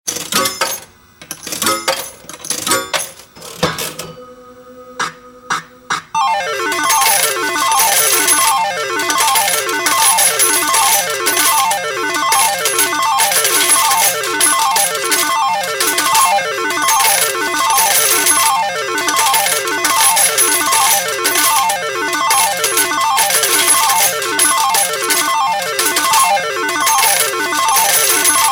slot-machine-payout_24797.mp3